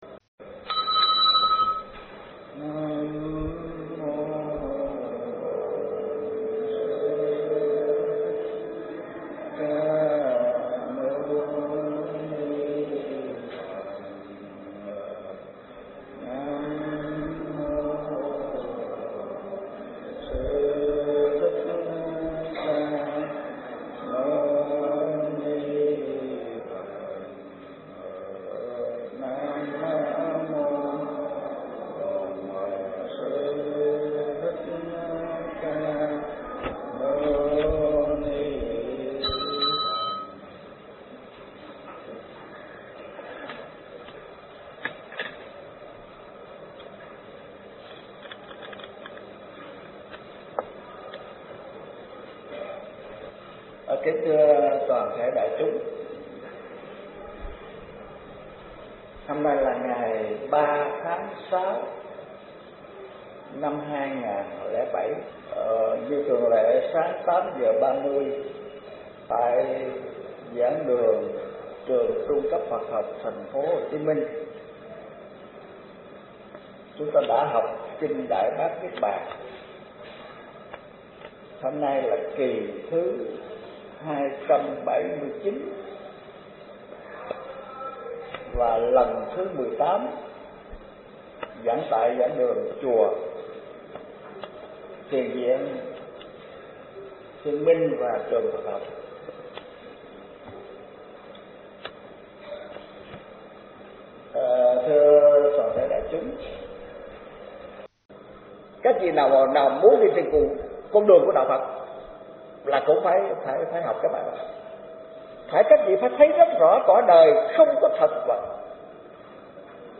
Bài giảng Đại Bát Niết Bàn kinh (File mp3): Kỳ 261 – 280